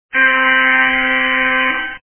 Descarga de Sonidos mp3 Gratis: alarma 1.
alarm.mp3